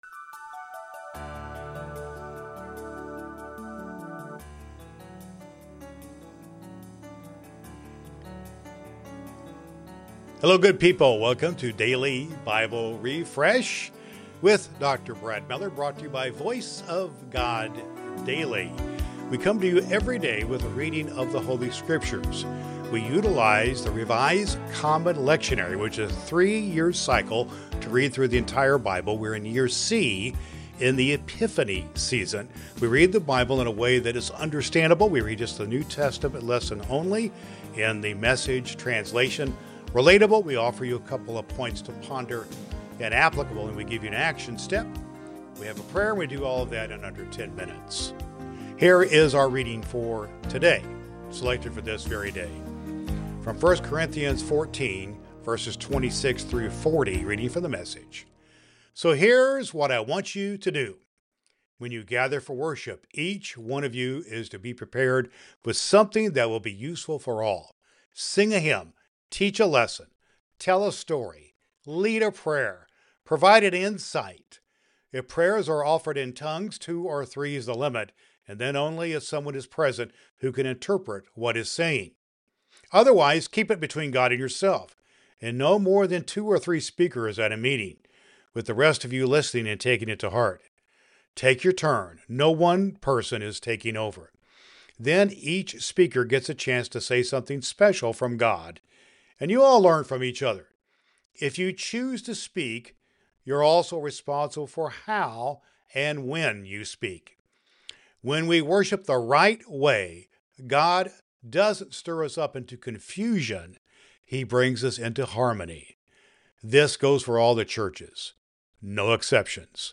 • Understandable: A reading from the New Testament (usually the Gospel) selected from the Revised Common Lectionary using "The Message" translation.
• A prayer for your day.